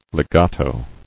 [le·ga·to]